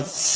Index of /m8-backup/M8/Samples/breaks/breakcore/evenmorebreaks/v0x